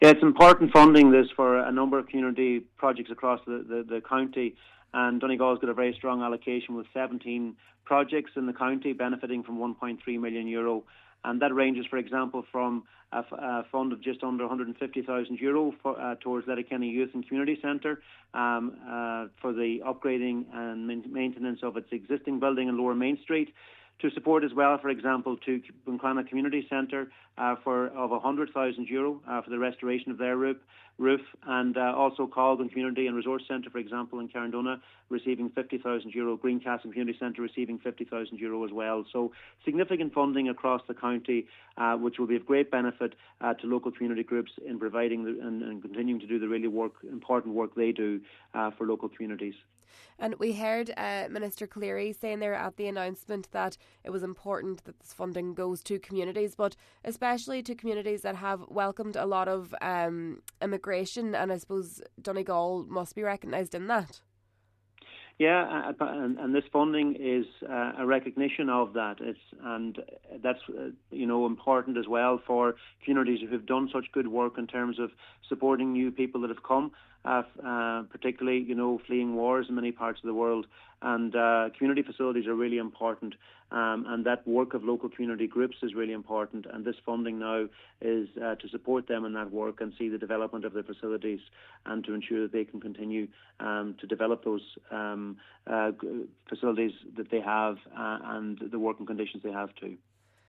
Minister of State for Sport and Postal Policy, Charlie McConalogue says the money awarded to the county is a recognition of the acceptance of new people to the community: